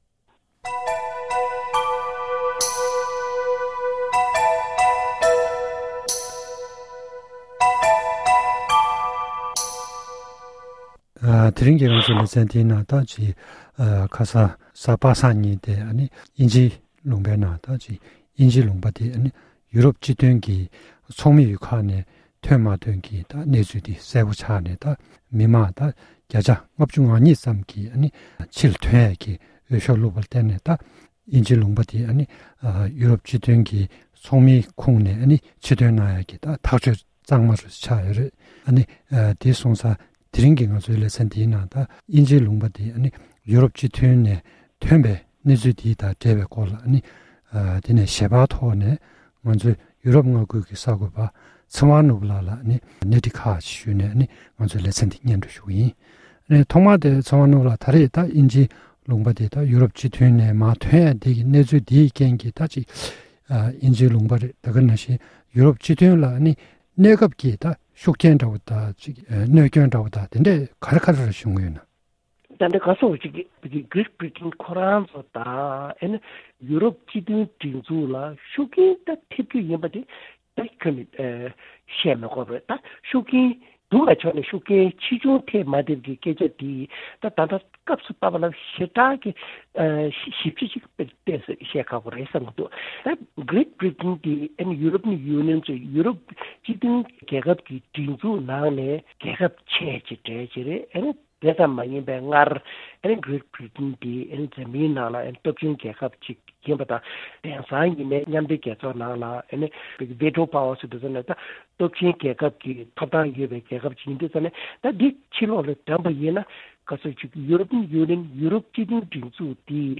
གནས་འདྲི་ཞུས་པ་ཞིག་གཤམ་ལ་གསན་རོགས་གནང་༎